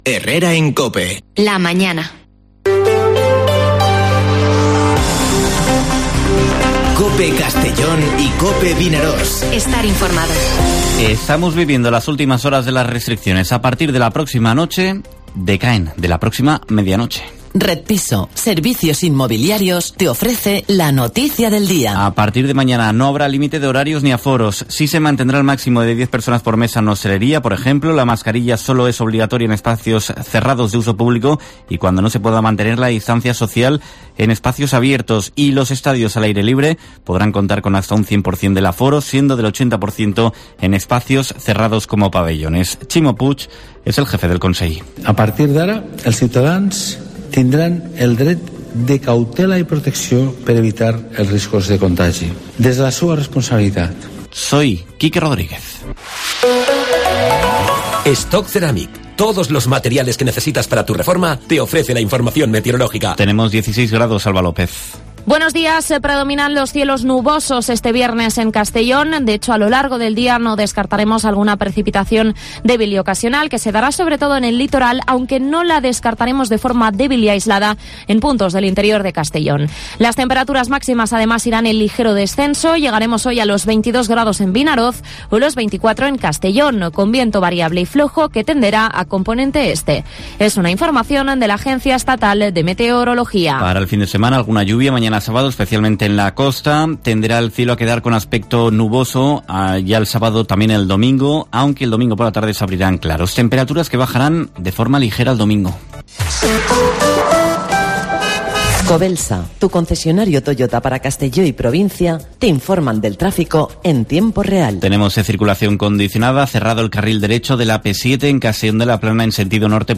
Informativo Herrera en COPE en la provincia de Castellón (08/10/2021)